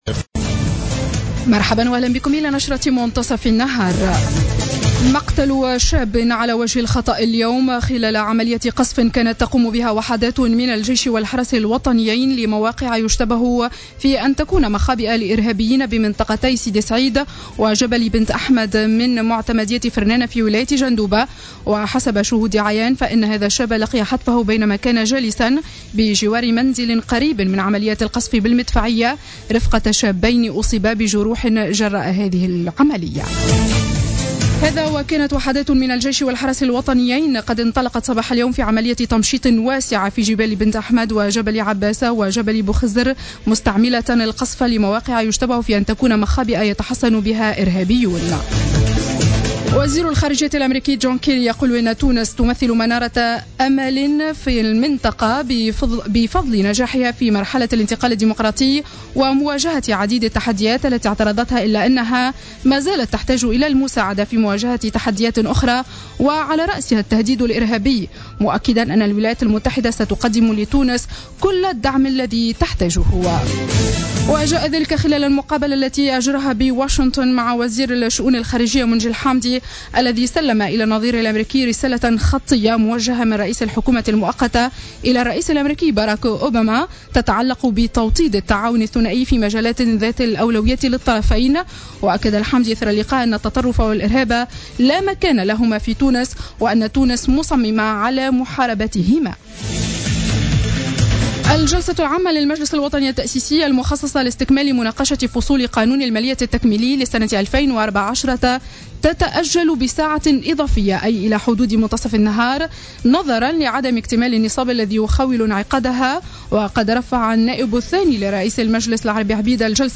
نشرة أخبار منتصف النهار ليوم الثلاثاء 05-08-14